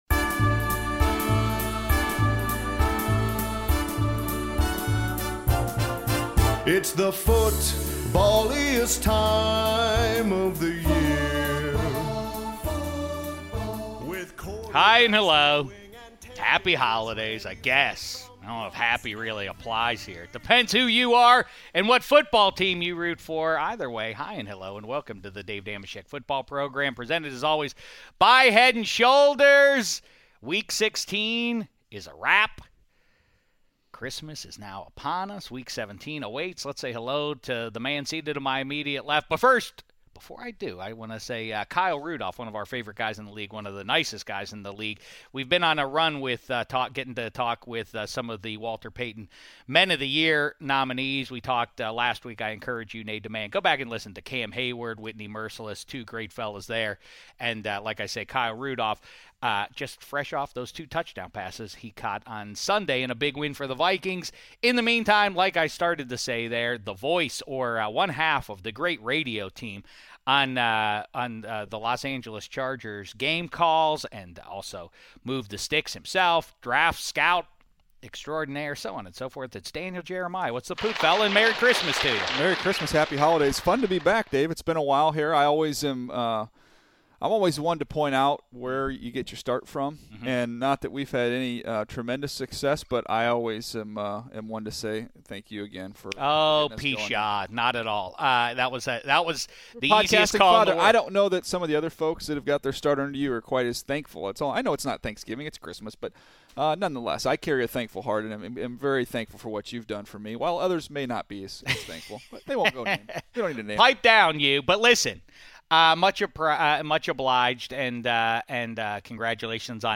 Week 16 Recap with Daniel Jeremiah + Kyle Rudolph Interview